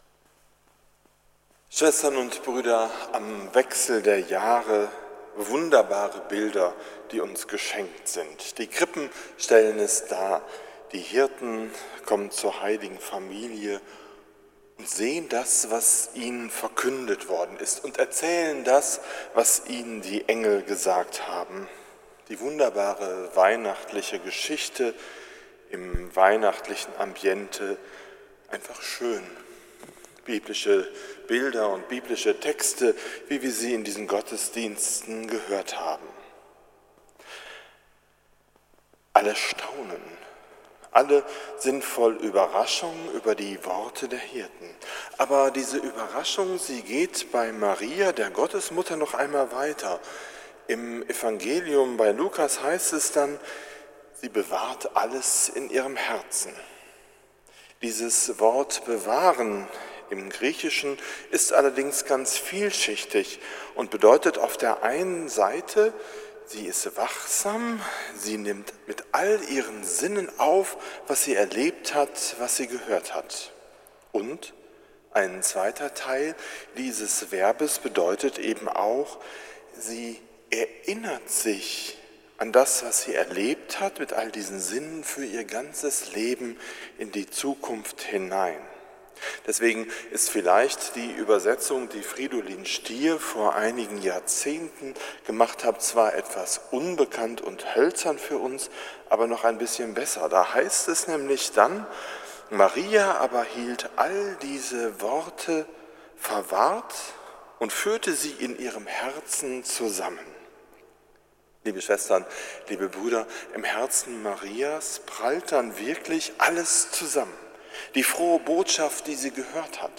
Predigt zum Jahreswechsel 2017 / 2018 – St. Nikolaus Münster